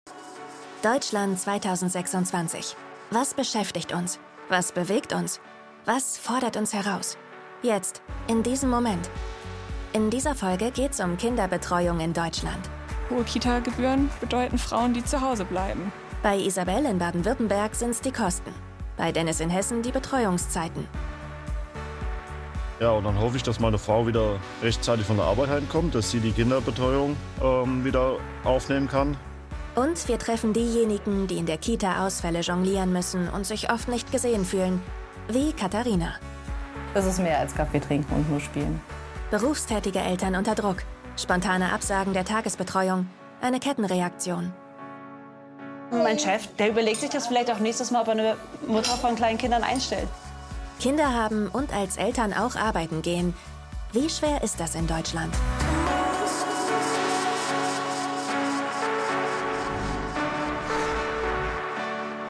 plakativ, sehr variabel
Jung (18-30)
Doku, Comment (Kommentar)